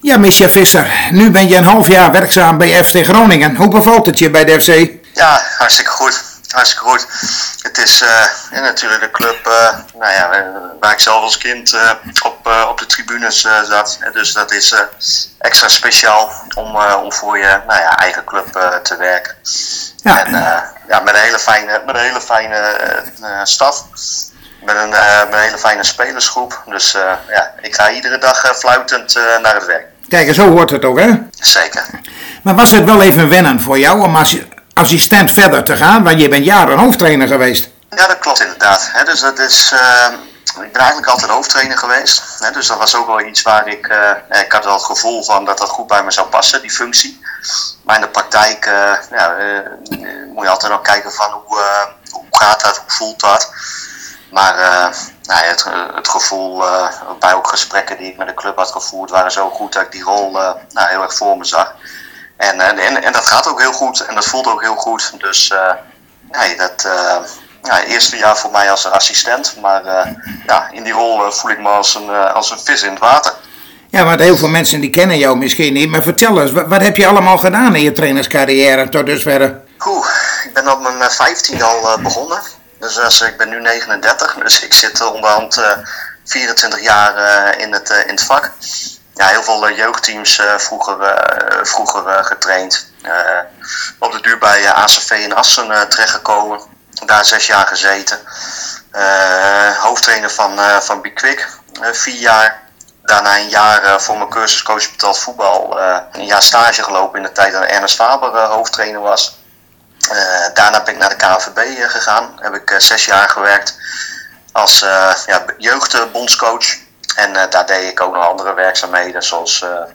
Uitgebreid interview